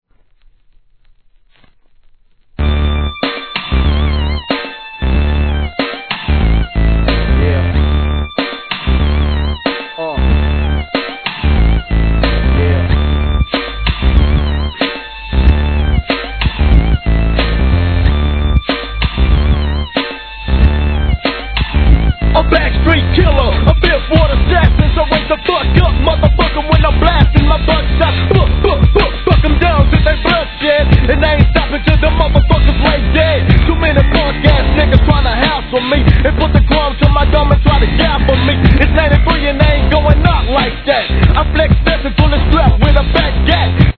1. G-RAP/WEST COAST/SOUTH